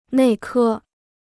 内科 (nèi kē): internal medicine; general medicine.